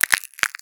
High Quality Footsteps / Glass Enhancement
STEPS Glass, Walk 04.wav